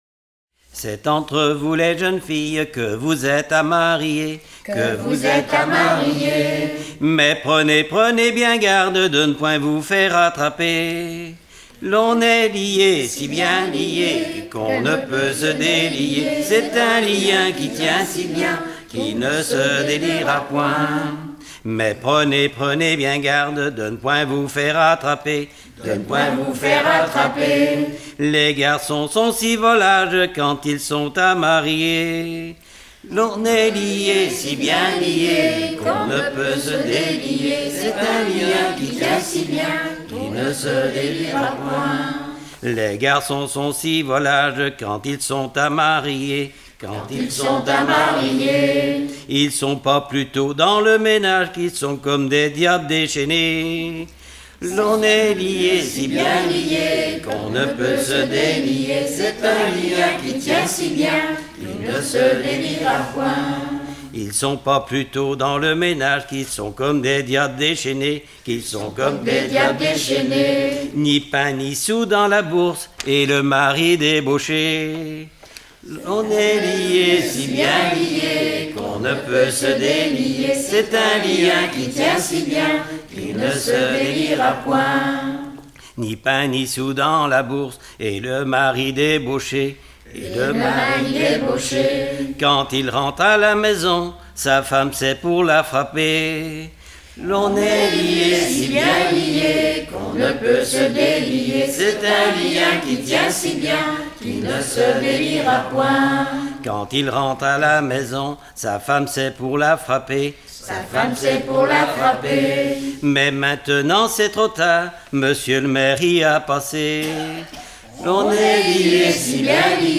Saint-Christophe-du-Ligneron
circonstance : fiançaille, noce ;
Genre laisse
Pièce musicale éditée